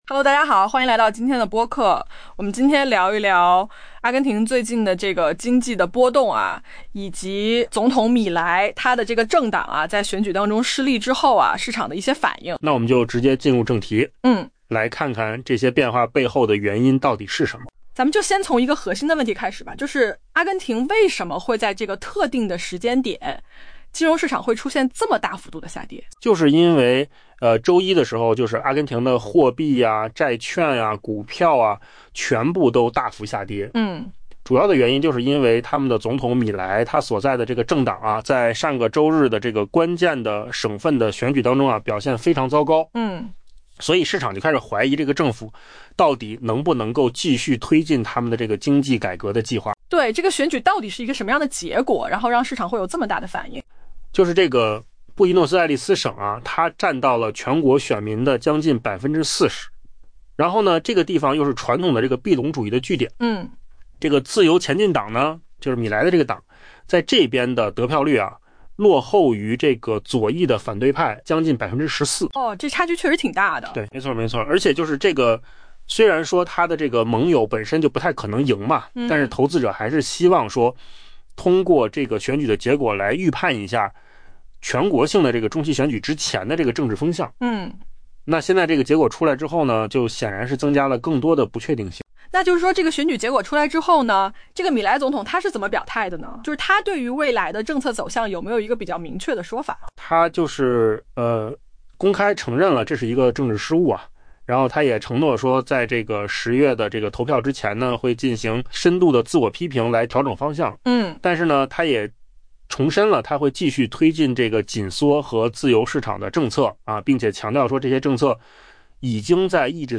AI 播客：换个方式听新闻 下载 mp3 音频由扣子空间生成 阿根廷货币、债券和股票全线暴跌。